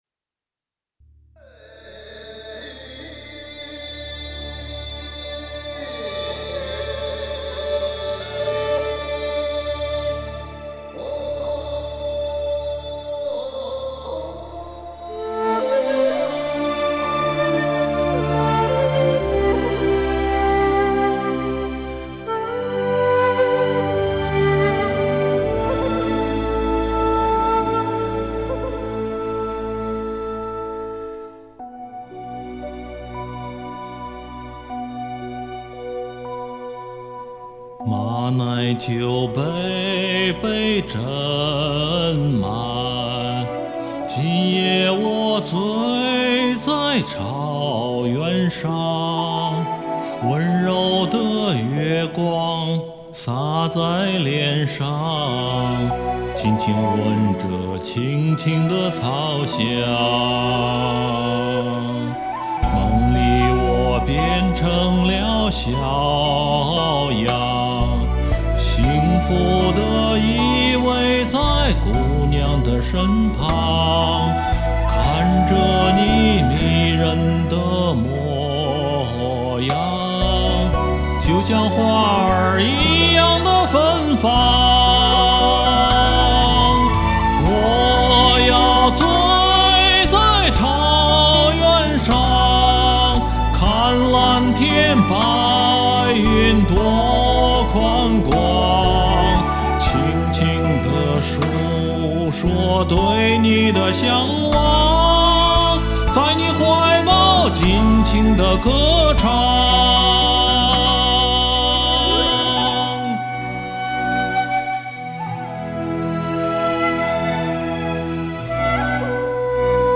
俺喜欢速战速决，连学带录唱了三遍交作业。肯定很粗糙，老毛病了，呵呵。